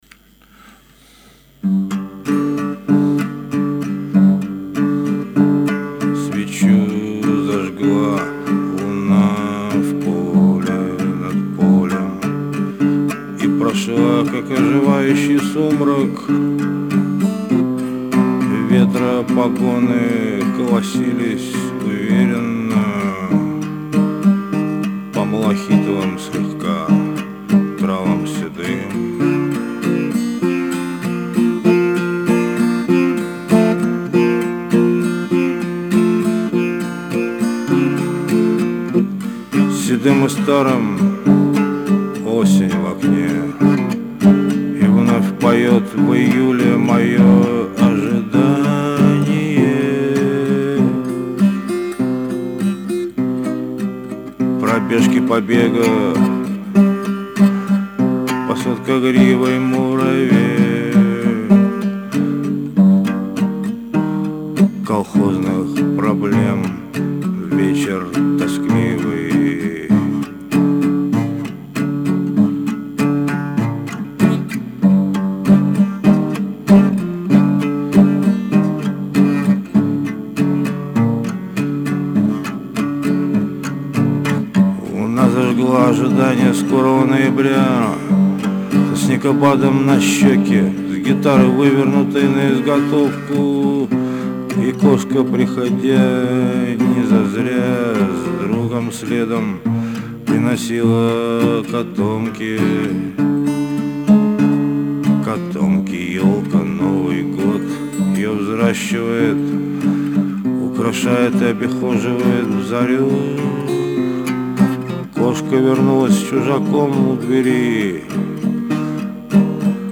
напел сегодняшнее стихо *, - 21 июня